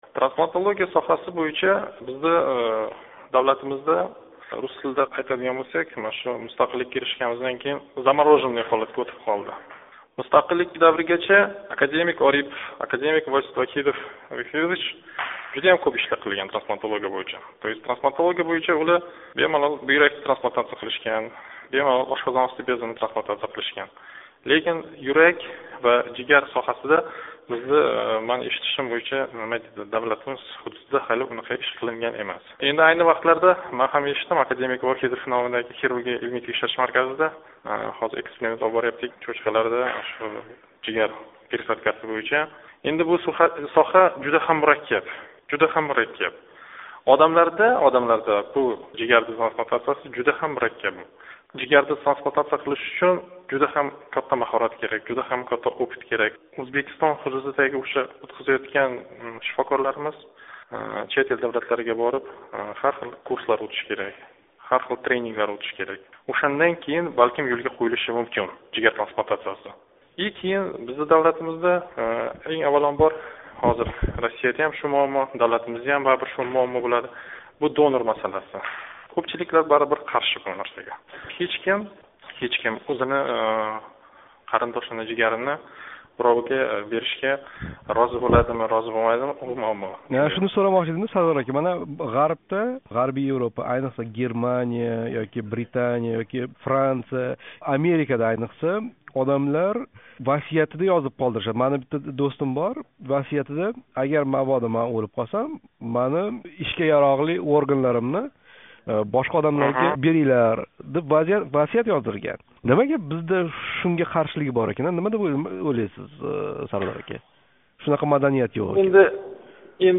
Жарроҳ билан трансплантология ҳақида суҳбат